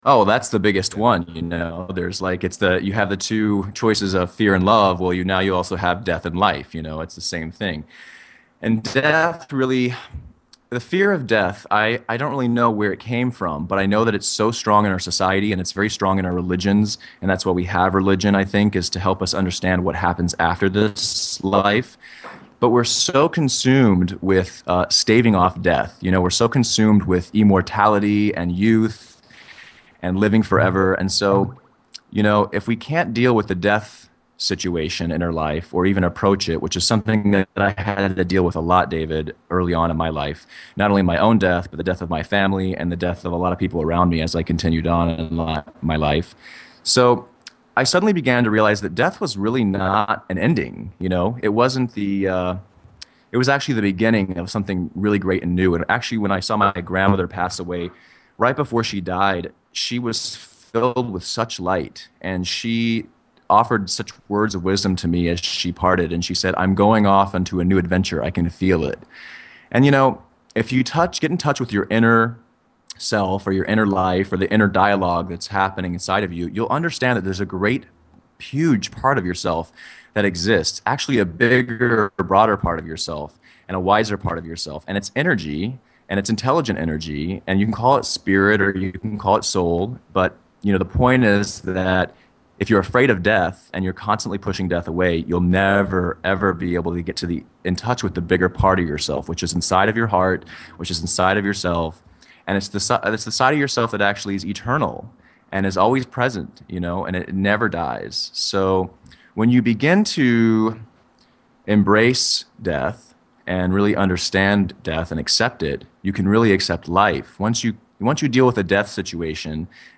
The Interview: